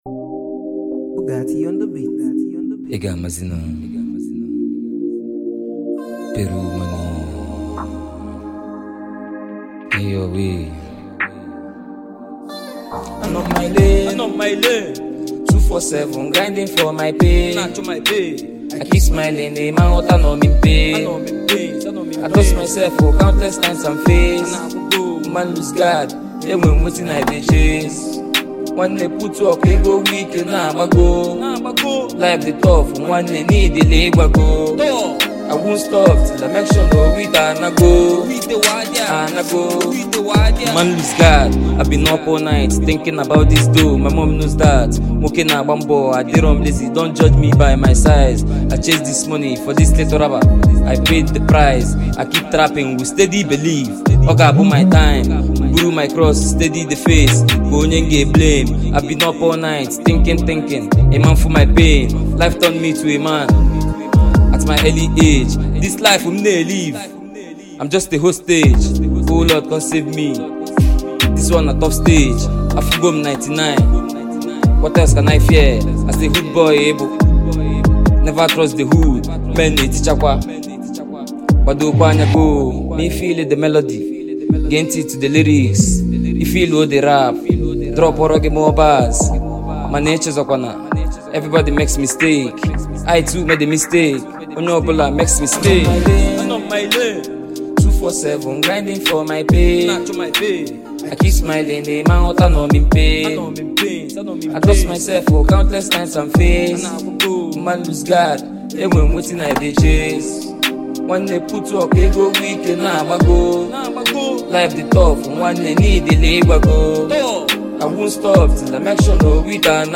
feel-good energy and irresistible rhythm